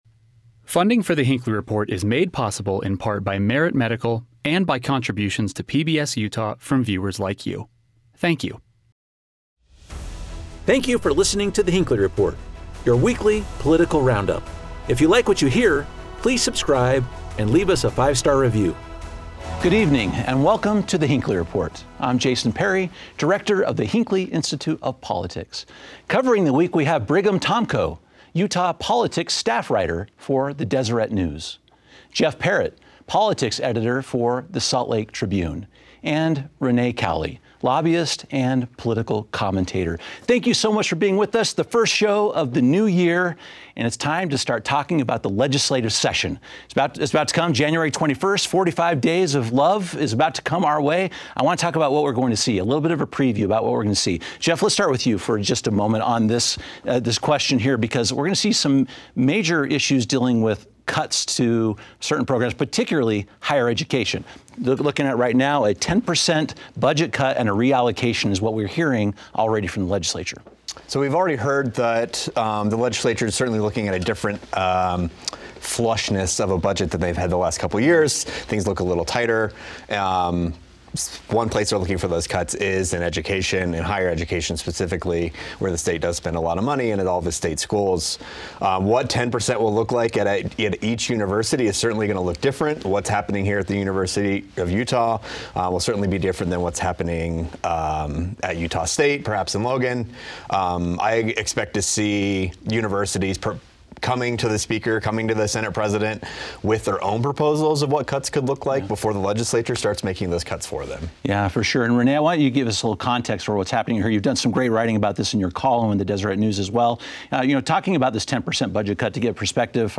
Our expert panel of political insiders discusses potential changes to higher education funding, election integrity, mail-in balloting, and judicial appointments, along with cuts to taxes on Social Security and shrinking the overall state budget. Plus, we remember the legacy of Former President Jimmy Carter, who recently passed away at the age of 100.